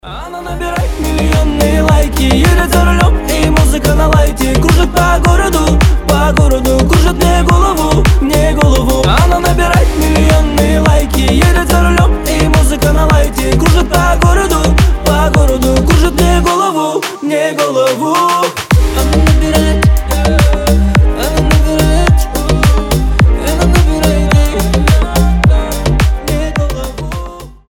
позитивные
легкие